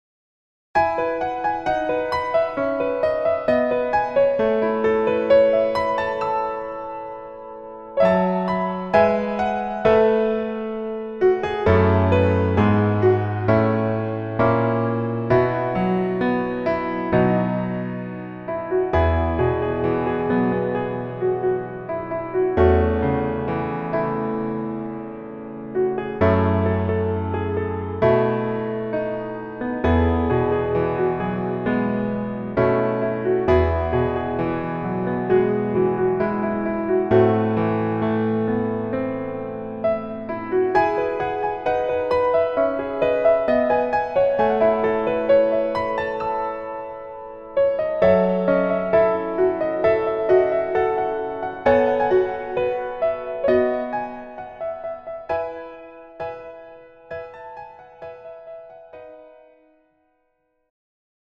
음정 원키 3:46
장르 가요 구분 Pro MR
Pro MR은 공연, 축가, 전문 커버 등에 적합한 고음질 반주입니다.